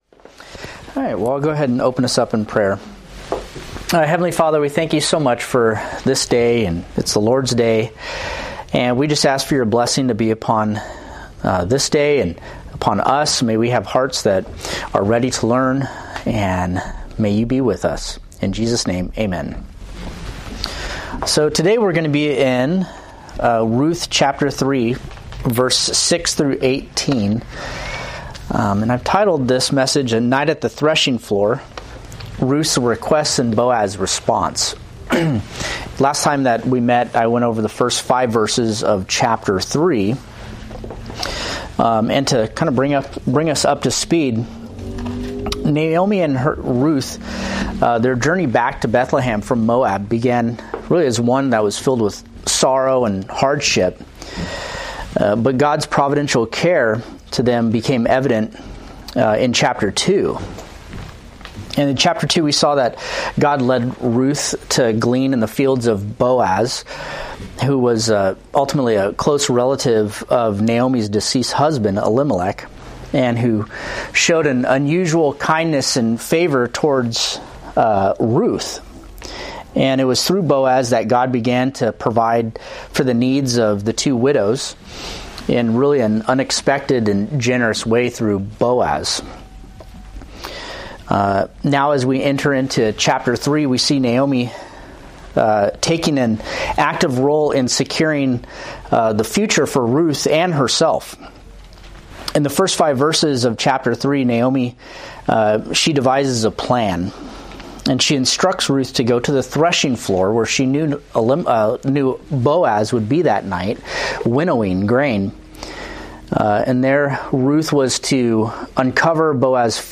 Date: Nov 10, 2024 Series: Ruth Grouping: Sunday School (Adult) More: Download MP3